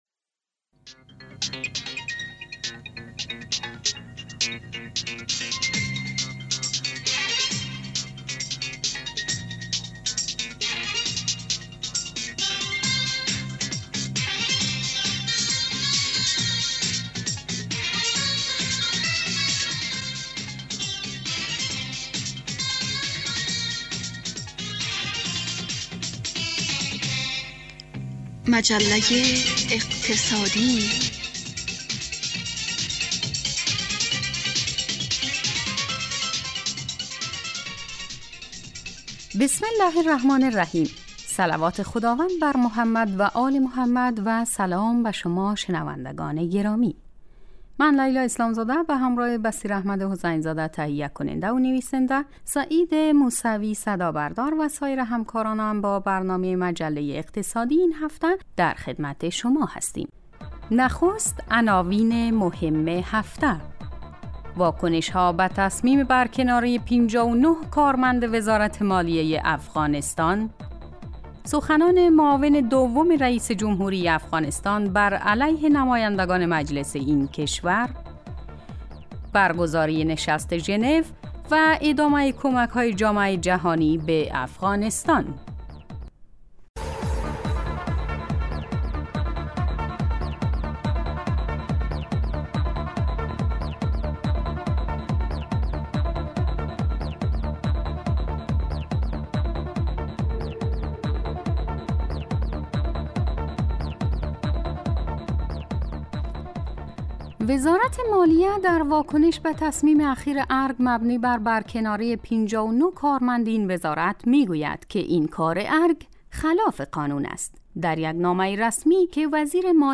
گوینده